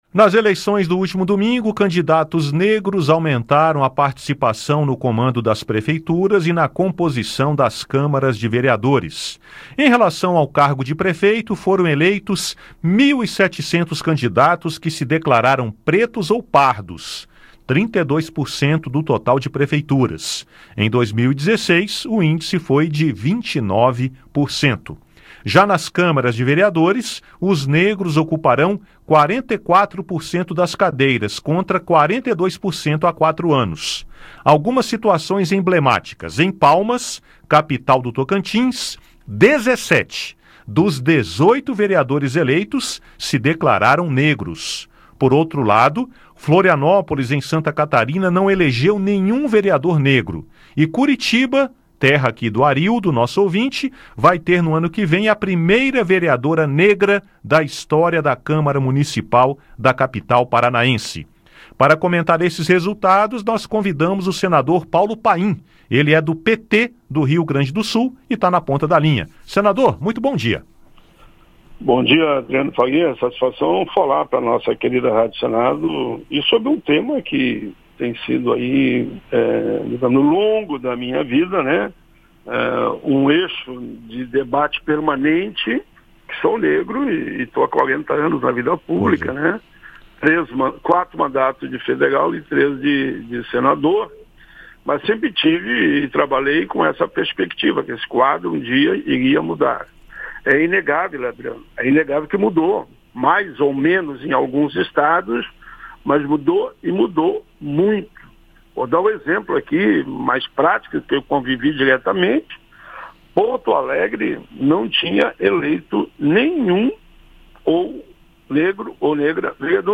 Entrevista: Aumenta o número de negros eleitos prefeitos e vereadores